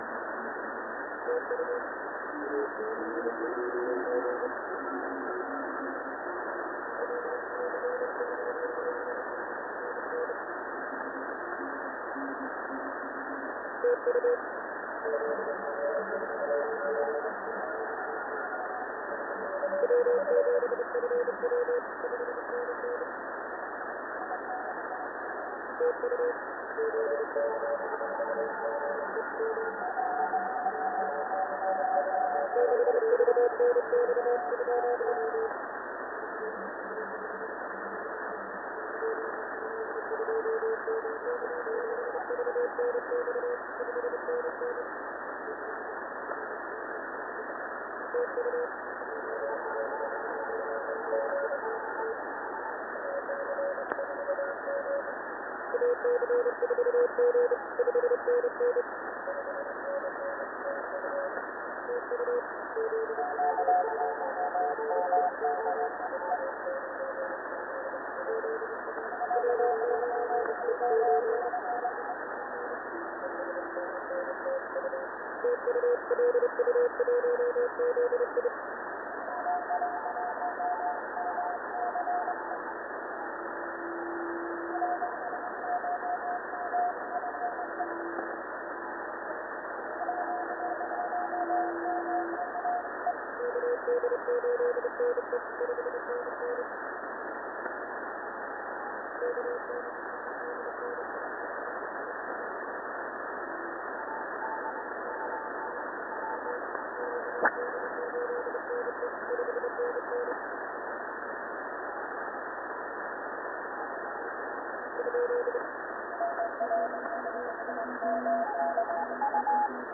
LU1ZI, 20CW - South Shetland Islands